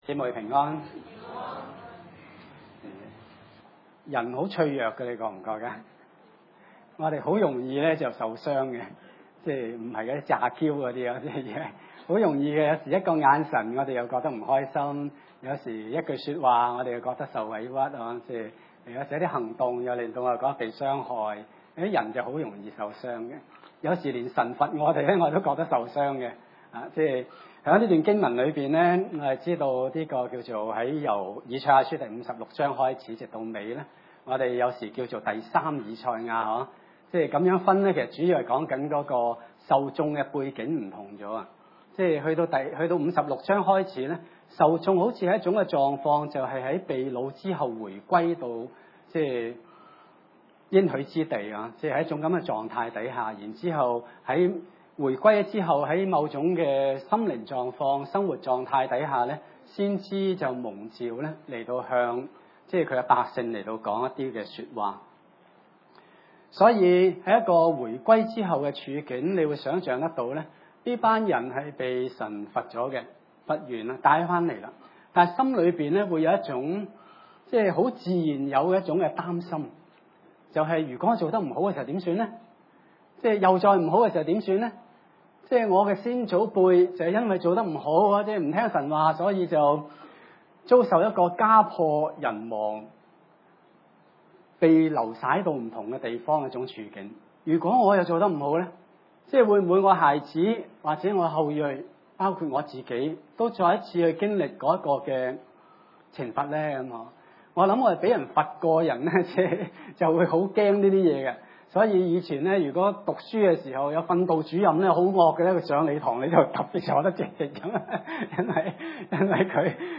經文: 以賽亞書57:14-21 崇拜類別: 主日午堂崇拜 14耶和華要說：你們修築修築，預備道路，將絆腳石從我百姓的路中除掉。